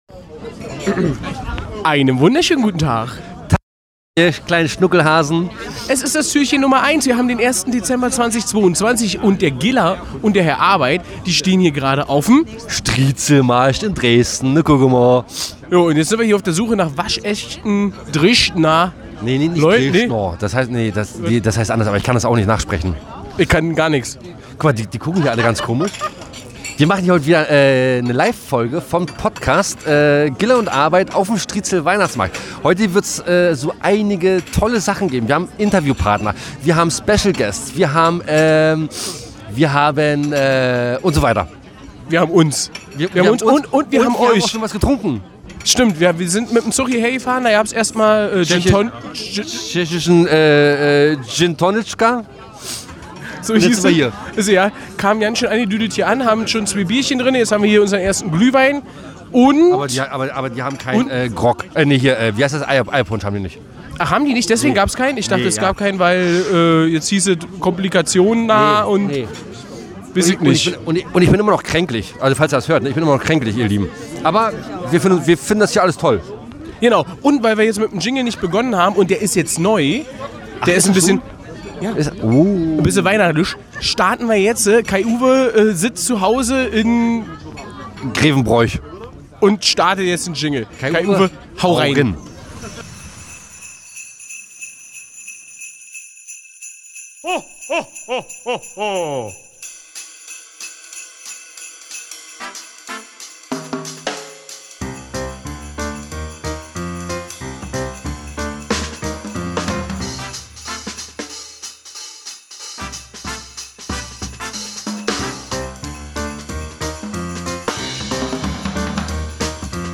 Wo die beiden sich rumtreiben? Dresden! Was sie da erleben erfahrt ihr fast live hier!